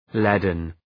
Shkrimi fonetik {‘ledən}